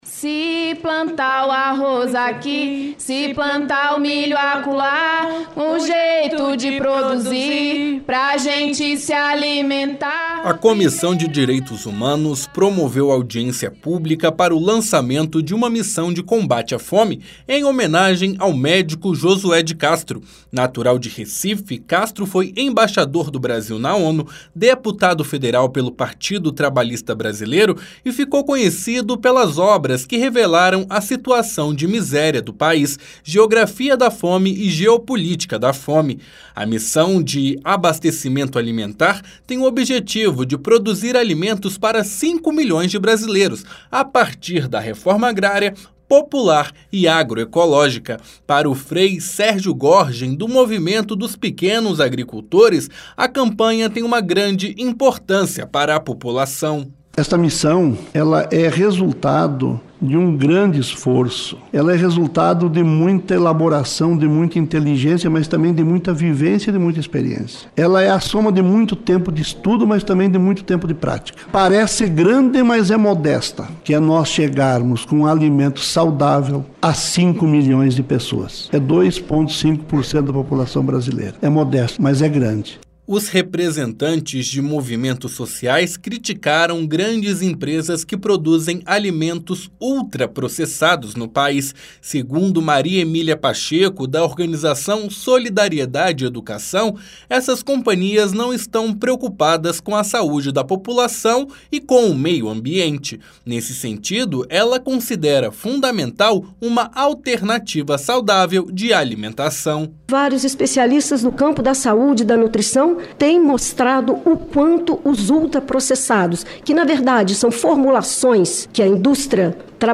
Nesta segunda-feira (11), a Comissão de Direitos Humanos e Legislação Participativa (CDH) promoveu uma audiência pública para discutir o lançamento da Missão Josué de Castro – Brasil no combate à fome. Participaram do debate pesquisadores e representantes de ministérios, de ONGs e de movimentos sociais, que criticaram os produtos ultraprocessados e defenderam a implantação de redes de abastecimento popular com alimentos agroecológicos. O objetivo da campanha é alcançar 5 milhões de brasileiros.